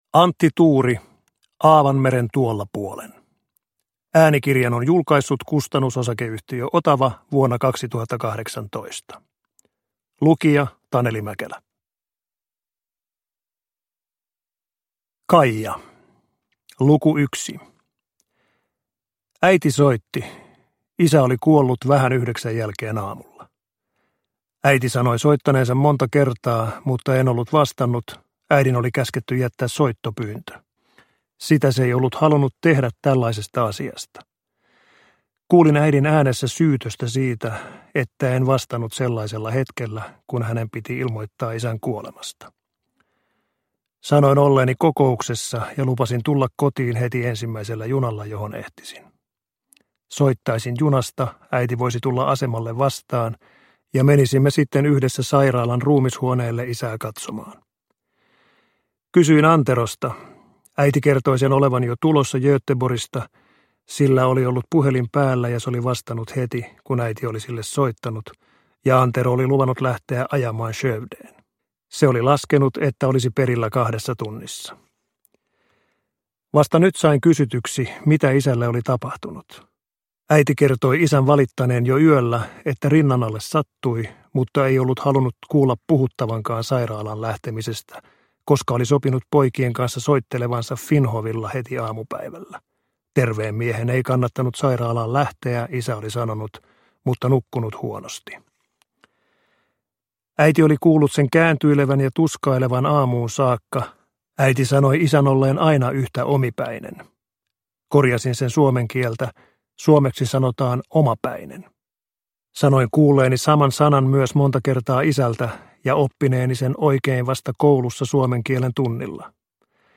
Aavan meren tuolla puolen – Ljudbok – Laddas ner
Uppläsare: Taneli Mäkelä